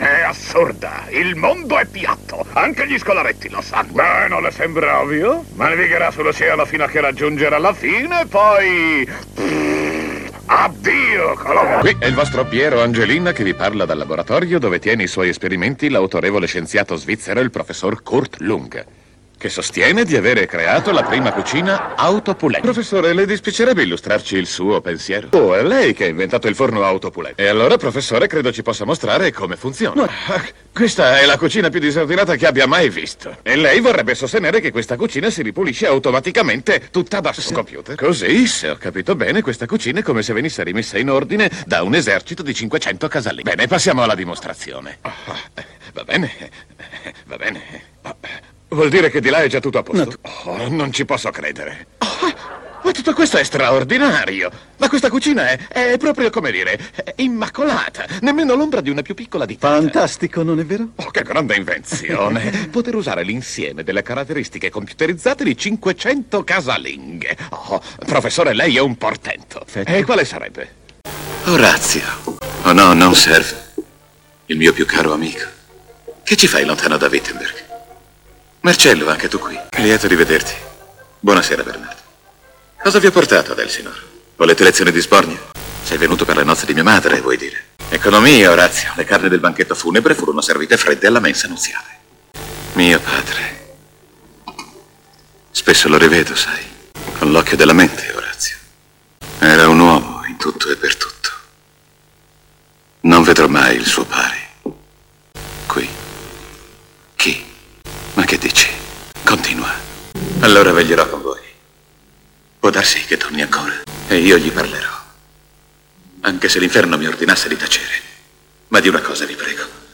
Il mondo dei doppiatori
in alcuni estratti dal telefilm "Wayne & Shuster", dal film "Amleto" (1964) e dal cartone animato "High School Invasion".